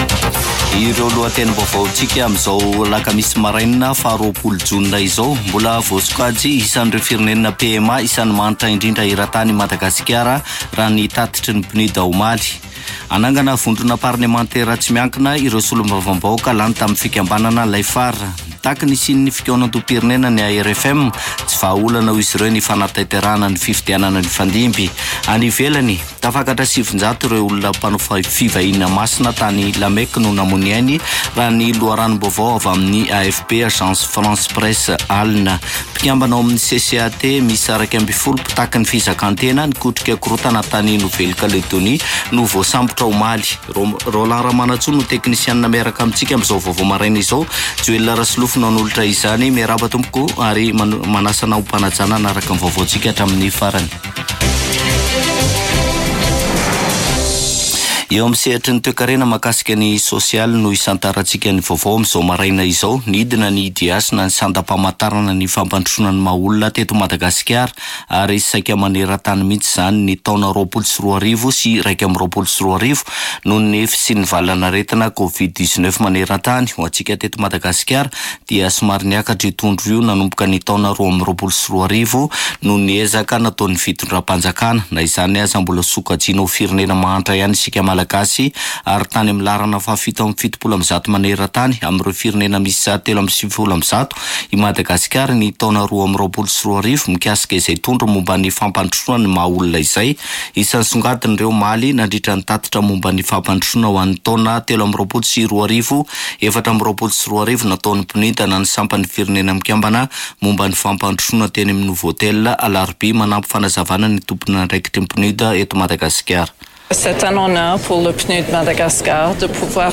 [Vaovao maraina] Alakamisy 20 jona 2024